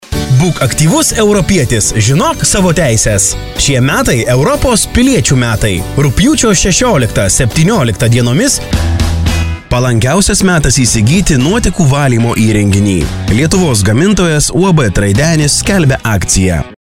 Lithuanian voice over